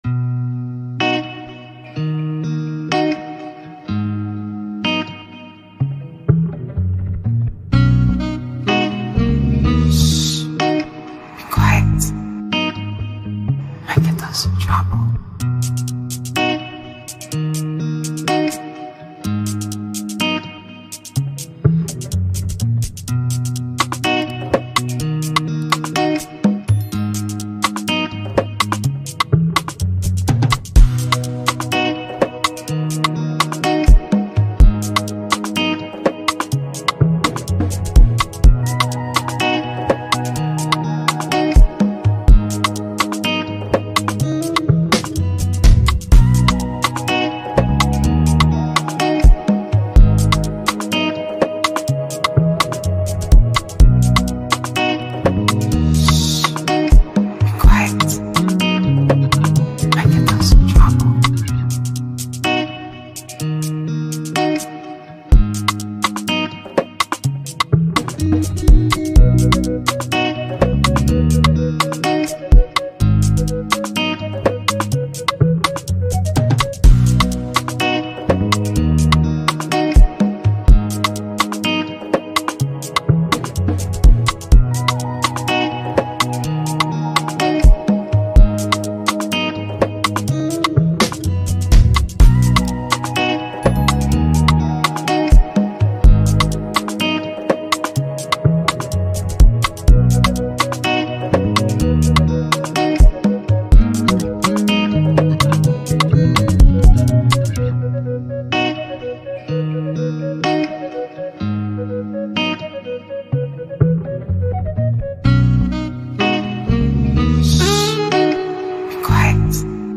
With its infectious rhythm and dynamic elements
this instrumental promises a melodic journey.